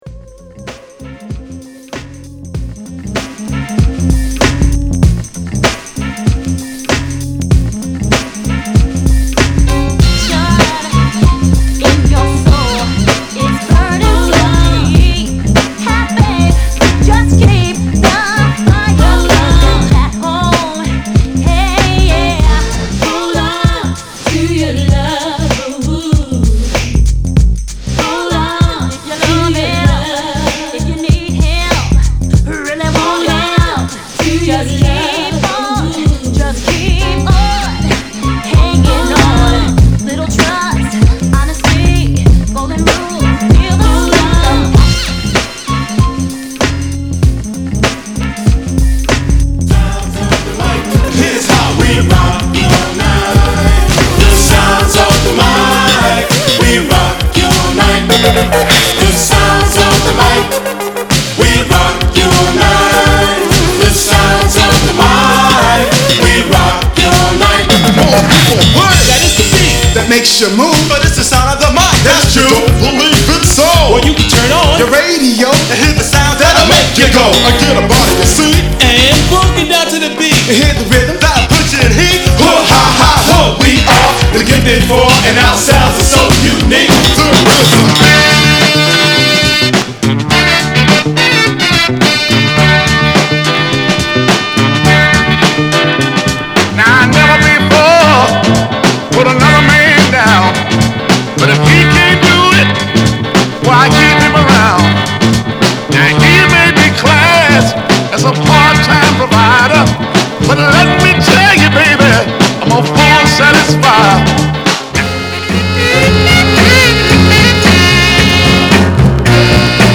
BPM96